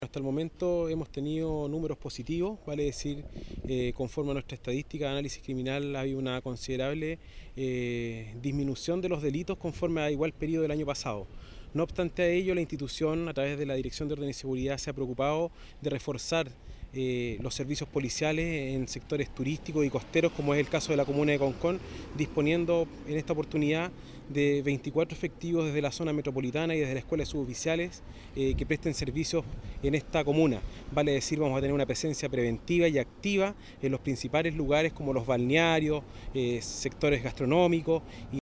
CU-MAYOR-DE-CARABINEROS-VERANO-SEGURO.mp3